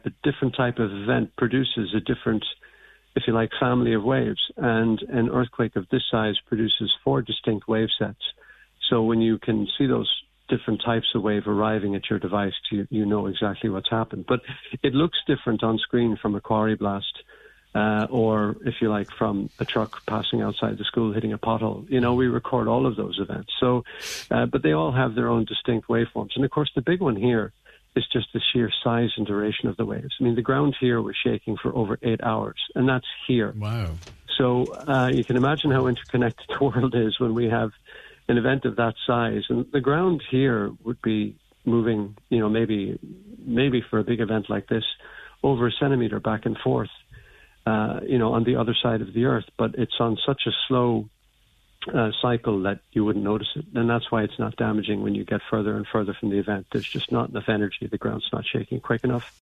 told today’s Nine til Noon Show, that while seismic waves reached Donegal, they were too weak to cause any damage: